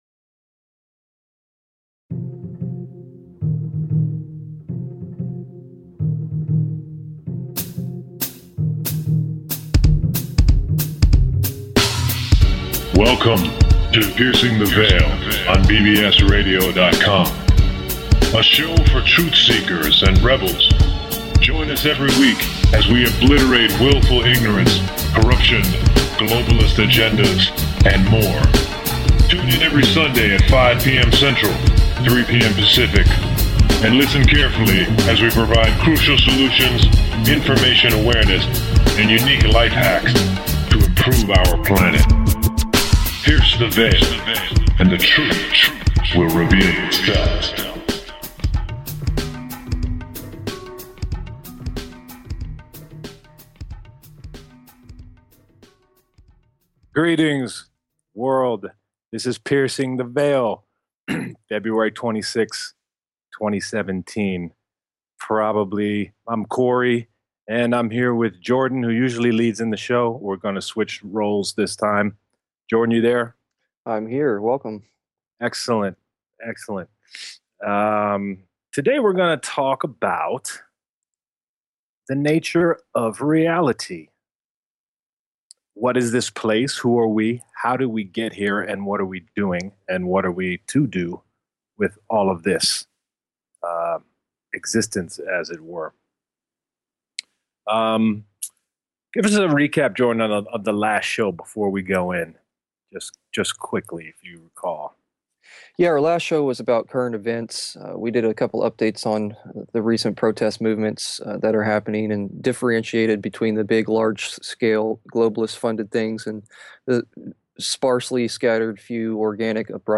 Topic Selected? The nature of the universe. Discussion ensues.